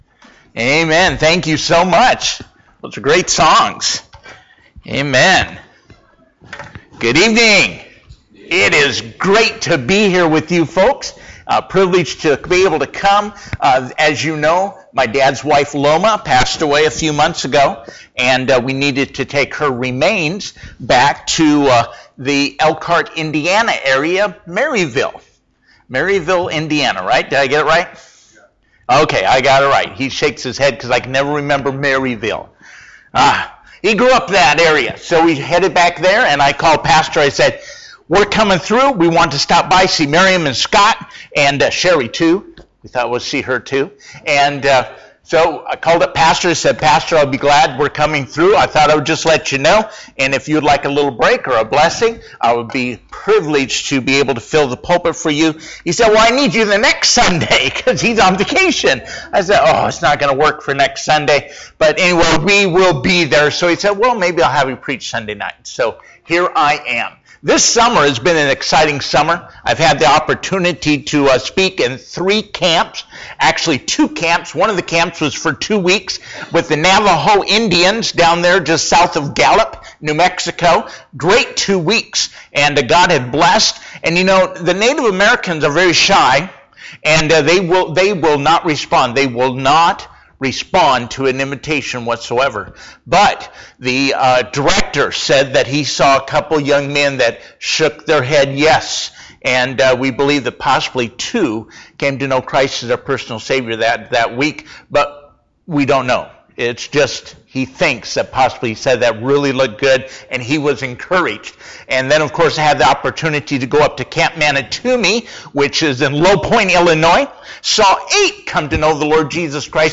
Sunday PM